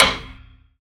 taiko-normal-hitclap.ogg